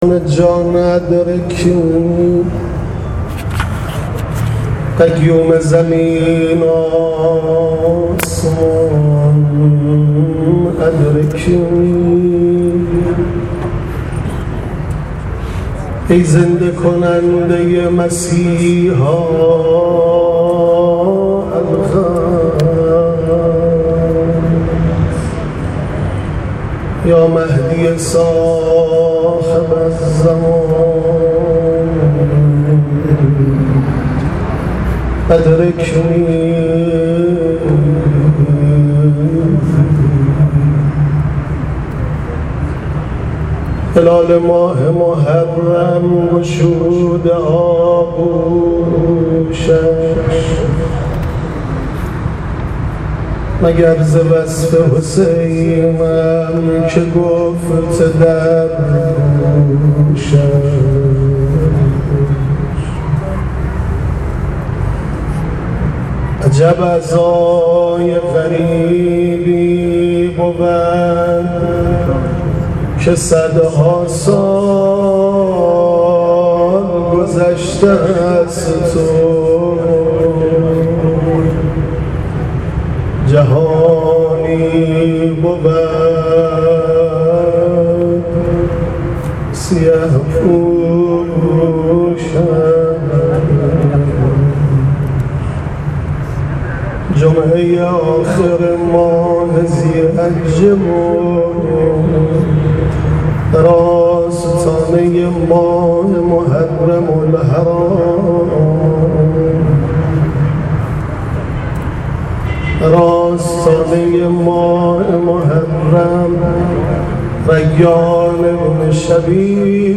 ورودیه محرم
در جریان مراسم افتتاح طرح توسعه مذهبی فرهنگی مسجد الشهداء
صوت این مداحی که با حضور اقشار مختلف مردم در مسجد الشهداء تهران برگزار شد را گوش نمائید: انتهای پیام/